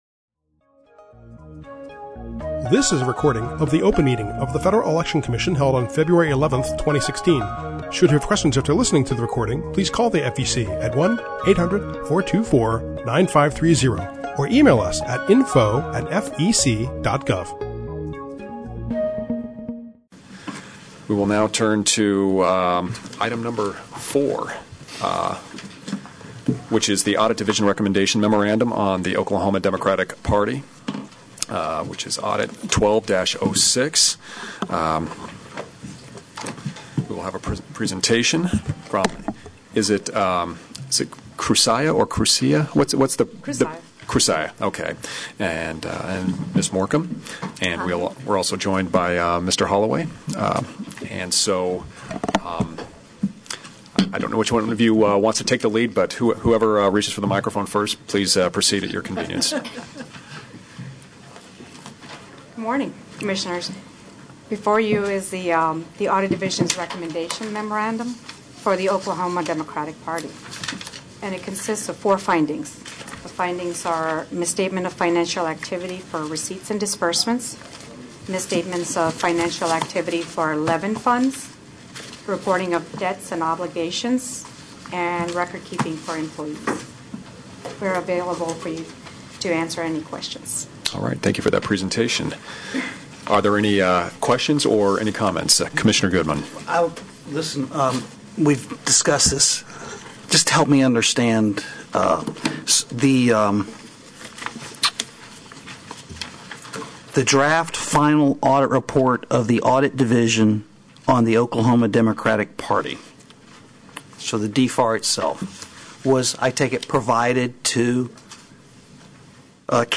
February 11, 2016 open meeting | FEC